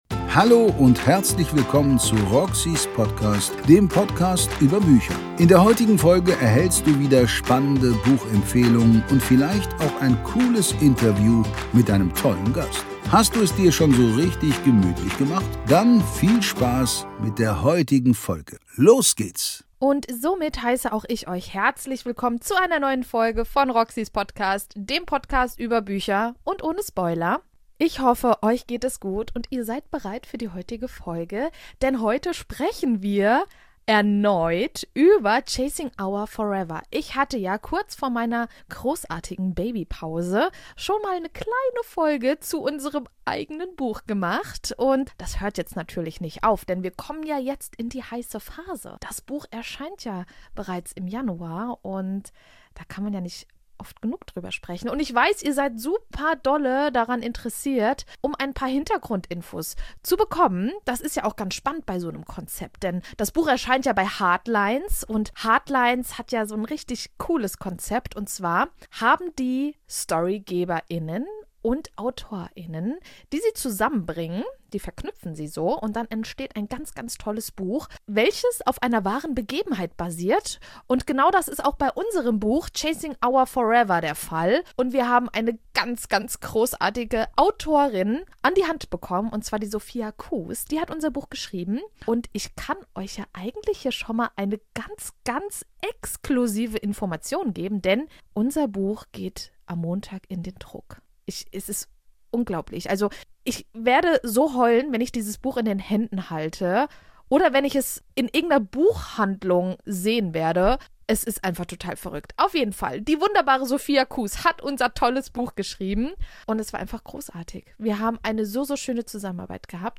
Erfahrt mehr über die Entstehung und lauscht uns bei unserem Talk zu.